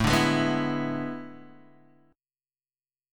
E7/A Chord